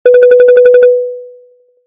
SE（着信音）
着信音 テテテテテテテダウンロード利用規約をご確認の上、ご使用ください。